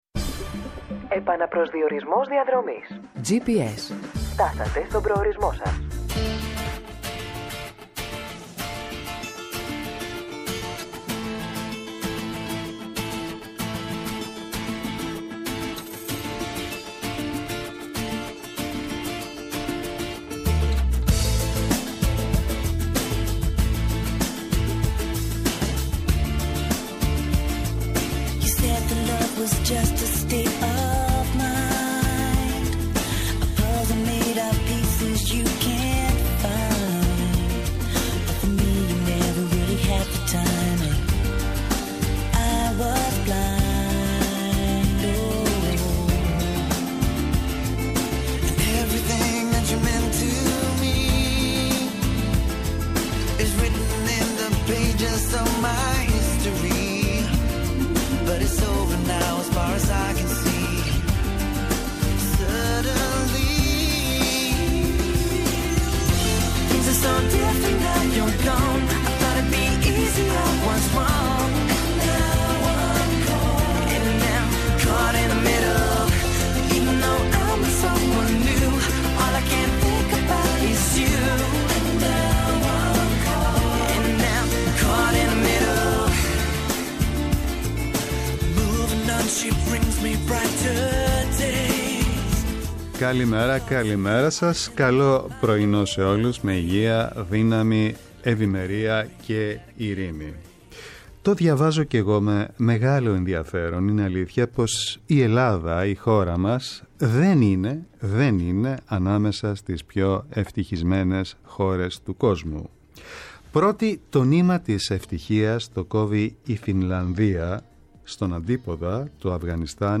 -Ο Θάνος Ασκητής, Ψυχίατρος-Αντιπεριφερειάρχης Υγείας Αττικής
-Ο Θεόφιλος Ξανθόπουλος, Βουλευτής ΣΥΡΙΖΑ